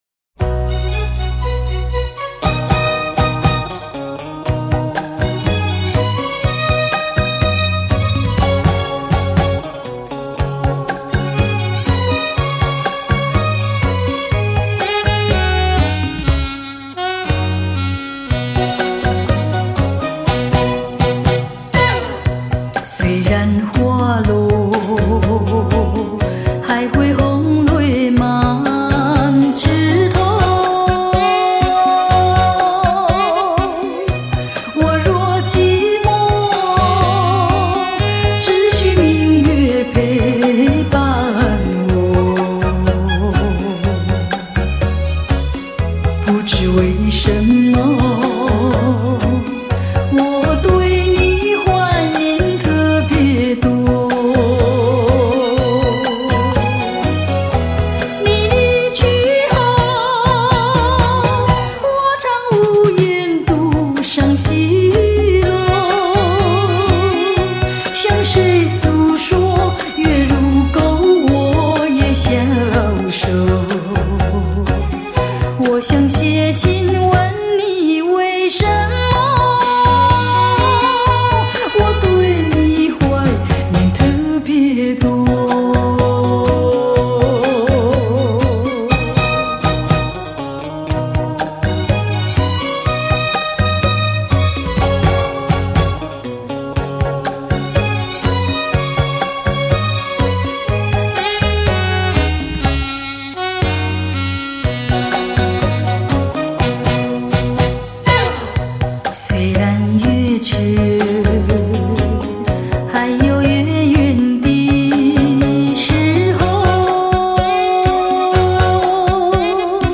录音室：Oscar Studio S'pore